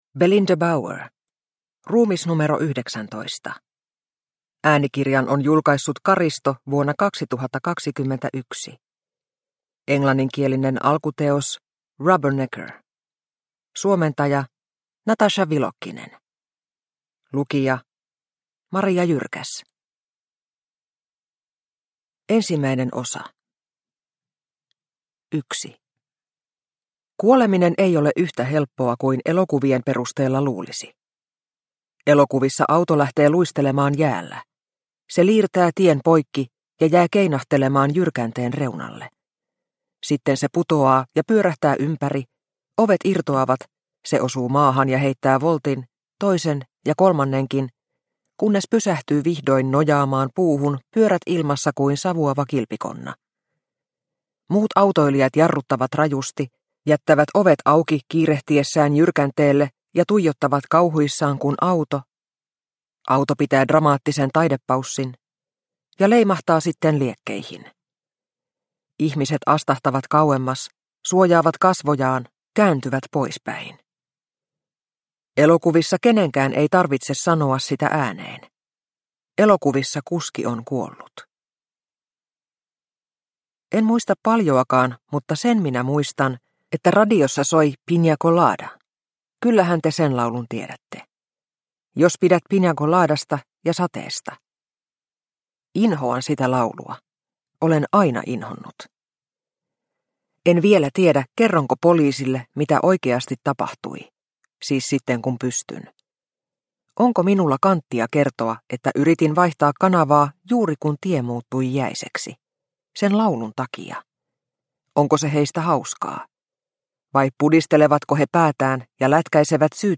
Ruumis nro 19 – Ljudbok – Laddas ner